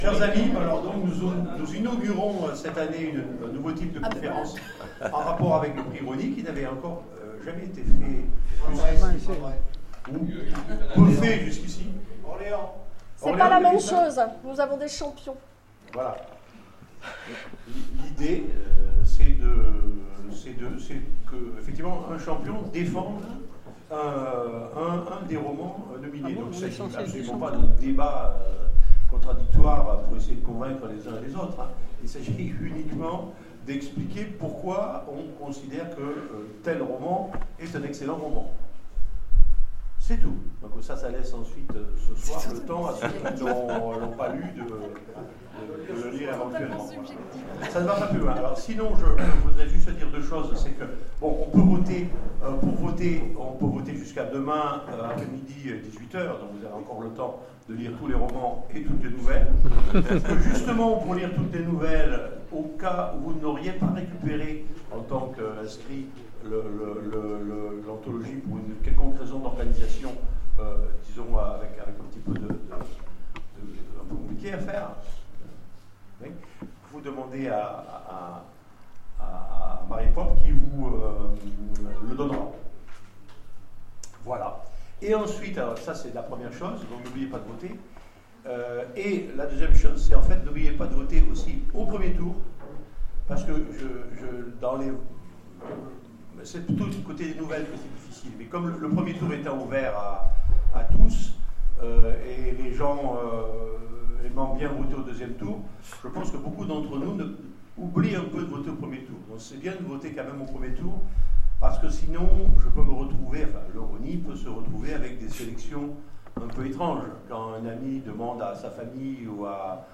Convention SF 2016 : Conférence Le Prix Rosny